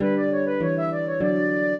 flute-harp
minuet12-8.wav